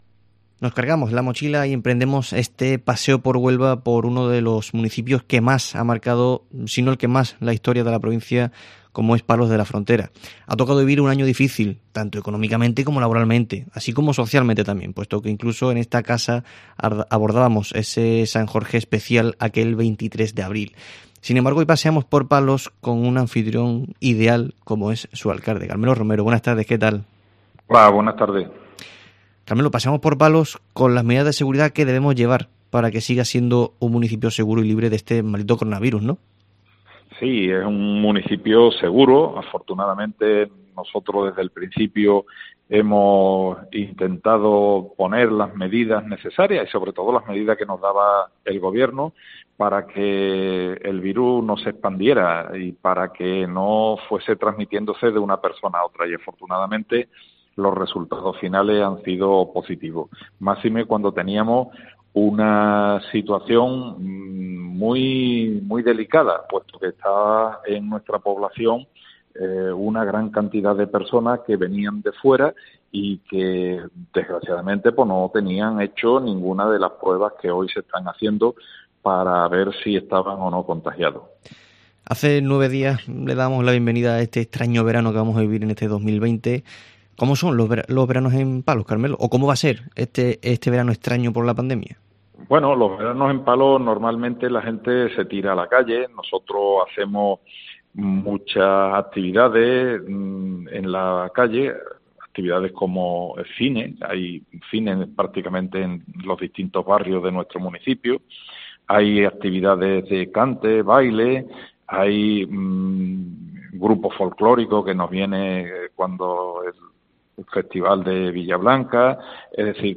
Hemos paseado por Palos de la Frontera con su alcalde, Carmelo Romero.
Carmelo Romero, alcalde de Palos de la Frontera